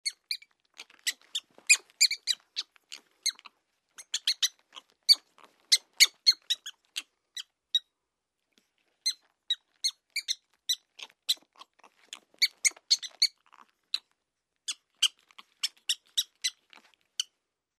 Звуки мелких грызунов